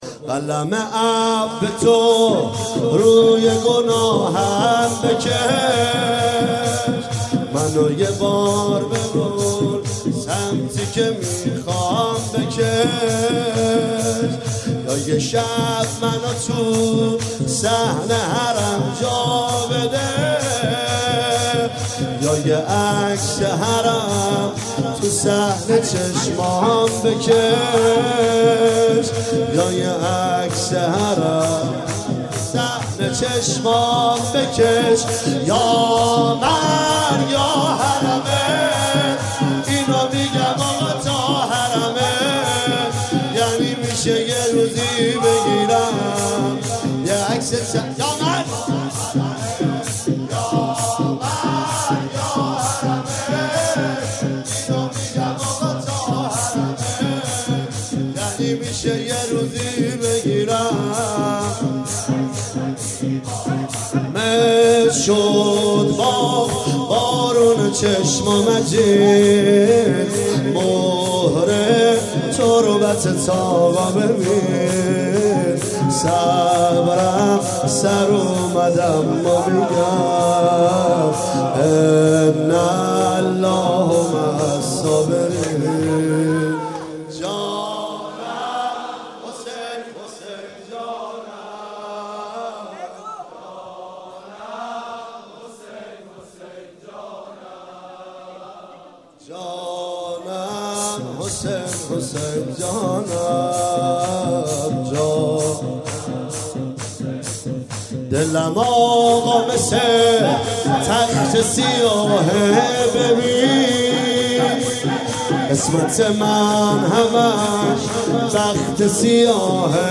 جلسه هفتگی ۹۶/۰۲/۰۱_شور_قلم عفتو روی گناهم بکش
مداحی